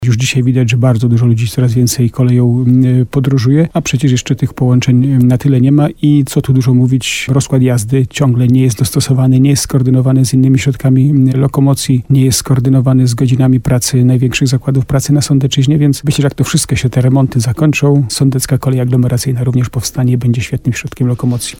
Jak przekonywał w programie Słowo za Słowo na antenie RDN Nowy Sącz burmistrz Starego Sącza Jacek Lelek, mieszkańcy coraz bardziej przekonują się do kolei.